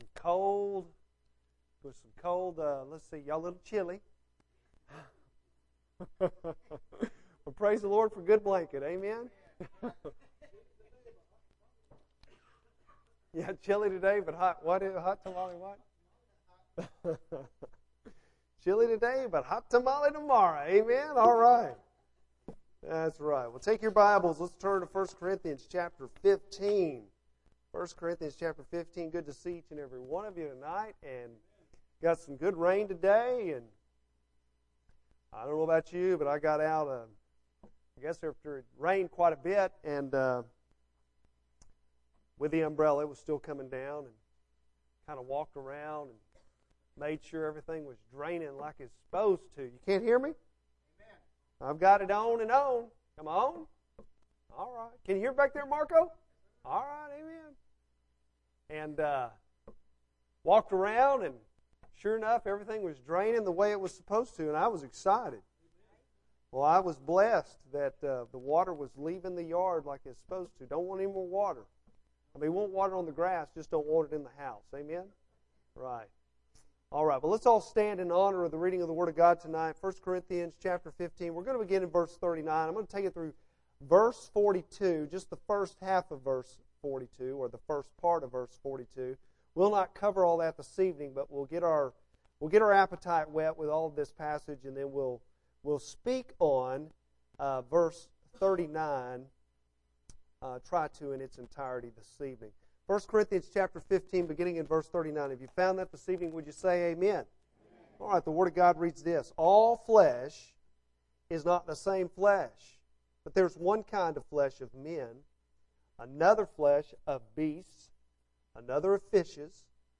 Bible Text: I Corinthians 15:39-42 | Preacher